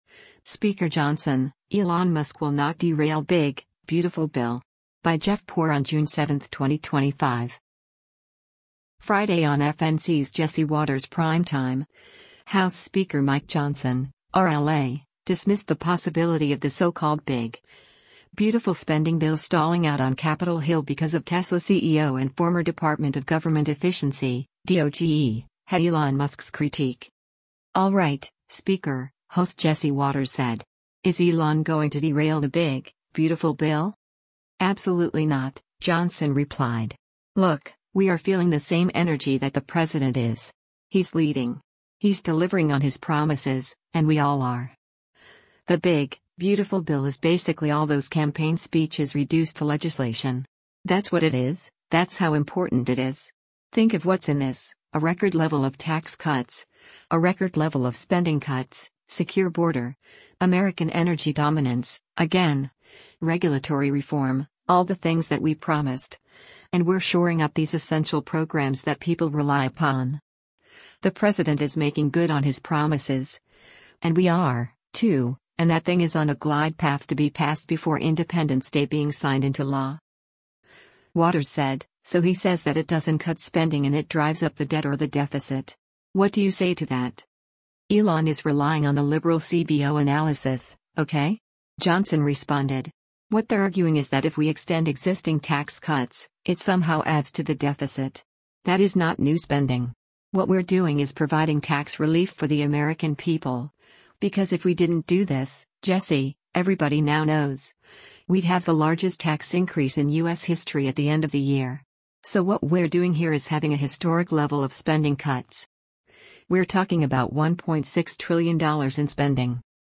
Friday on FNC’s “Jesse Watters Primetime,” House Speaker Mike Johnson (R-LA) dismissed the possibility of the so-called “big, beautiful” spending bill stalling out on Capitol Hill because of Tesla CEO and former Department of Government Efficiency (DOGE) head Elon Musk’s critique.